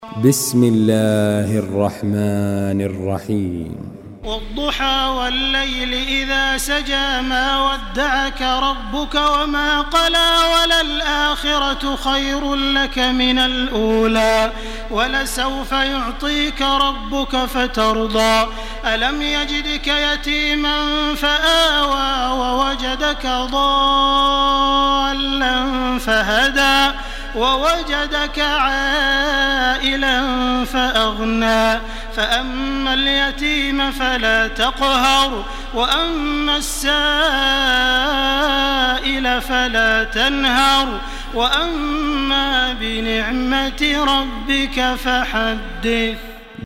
تراويح الحرم المكي 1429
مرتل